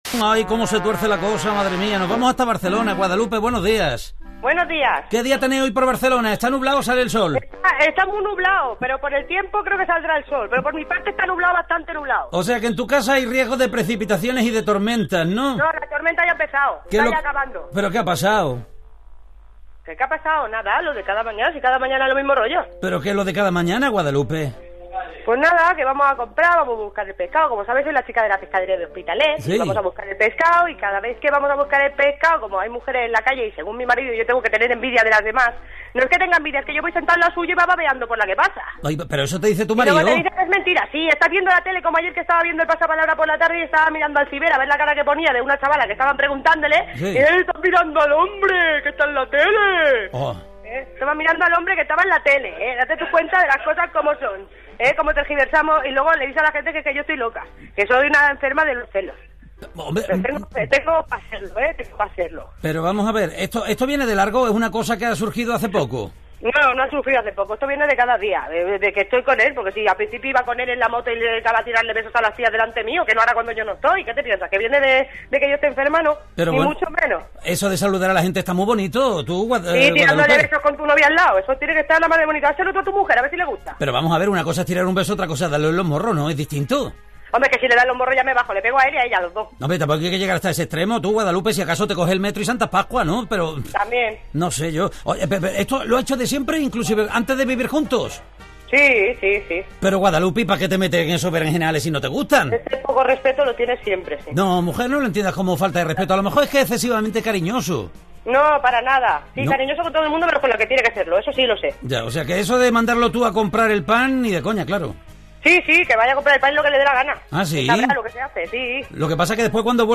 ¿Celos enfermizos o razones para tenerlos? Debate en directo!